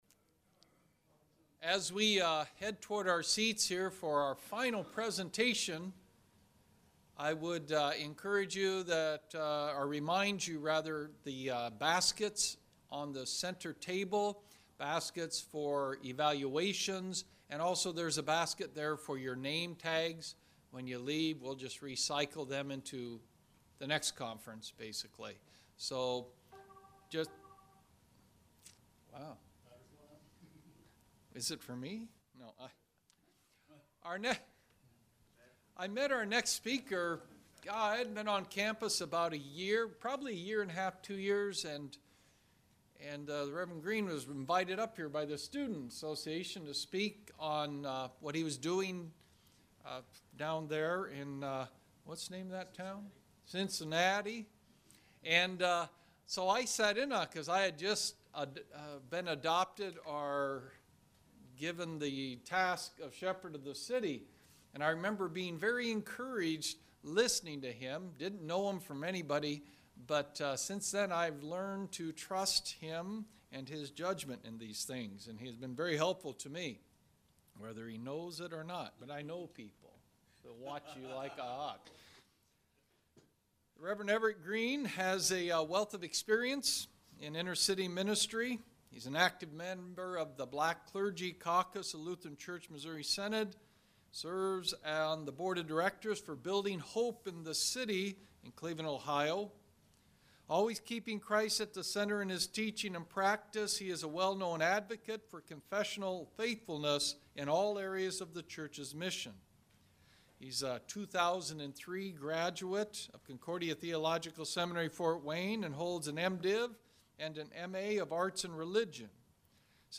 Additional Information: Collections This Item is in 1 Public Collection Urban Ministry Conference: In the World for Good 2013 by CTS This item is in 0 Private Collections Log in to manage and create your own collections.